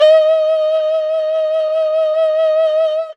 52-bi09-erhu-f-d#4.wav